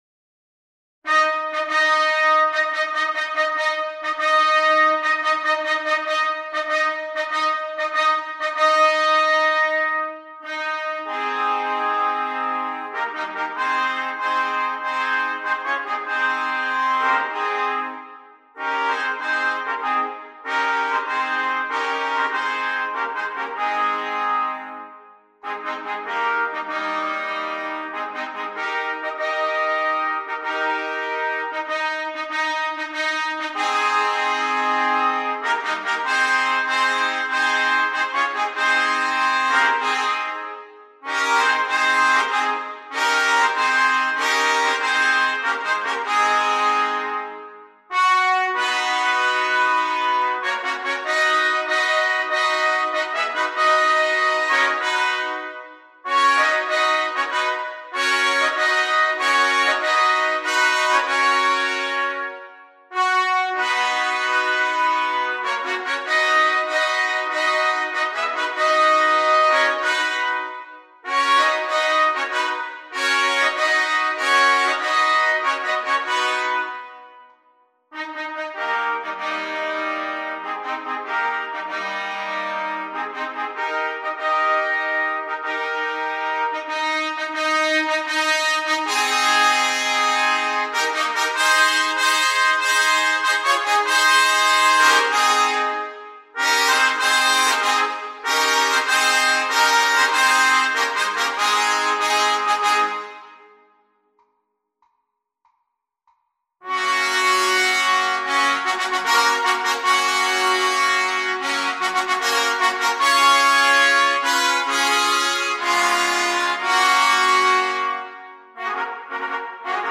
Quartet Trumpets
without solo instrument
Part 1: Bb Trumpet, Bb Cornet